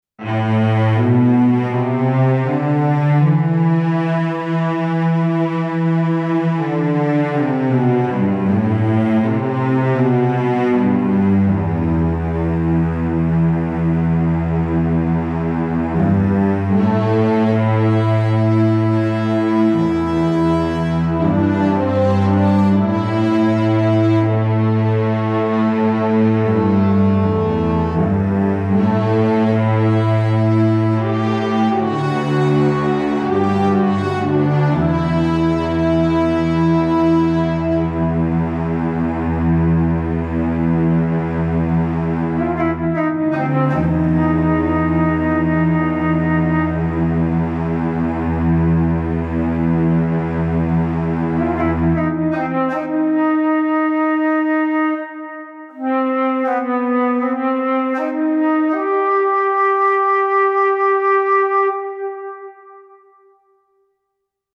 Incidental Music Samples